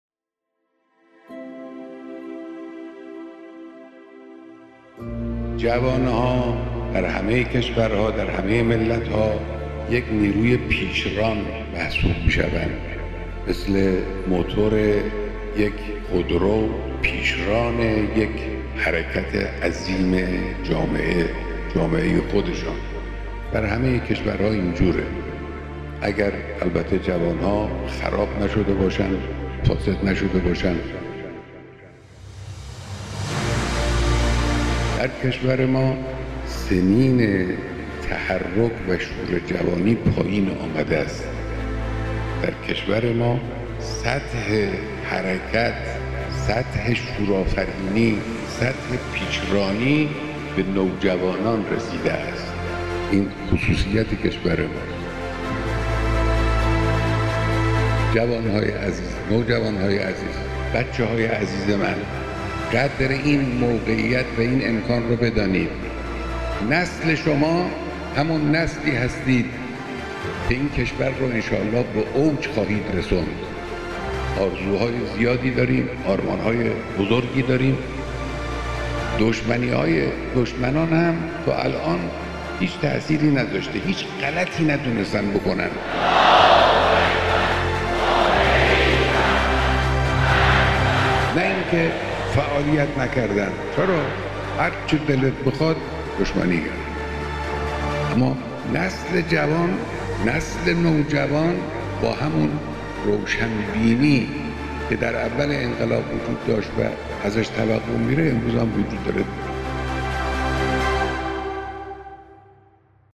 صداهنگ نوجوانان!